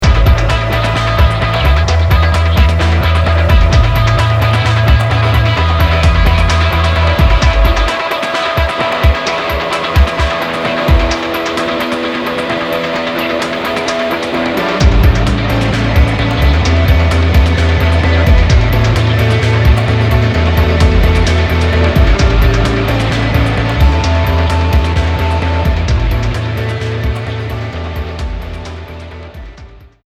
The results cohere into a sonic juggernaut.
(Instrumental)